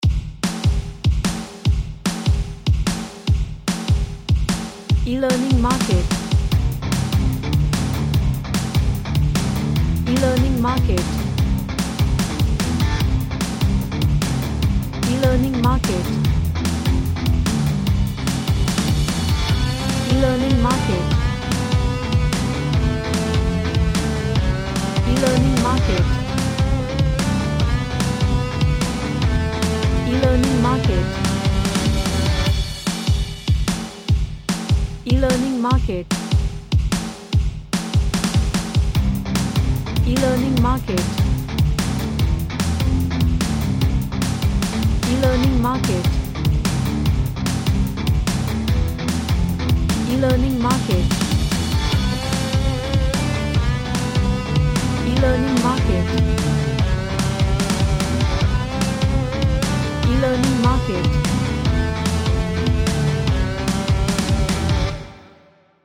An Energetic punk rock track with guitar riffs.
Energetic